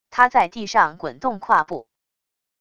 他在地上滚动跨步wav音频